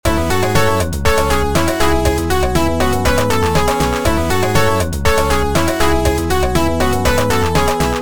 Just a funky disco loop you can use when a player wins big :)
funky-victory-loop_0.mp3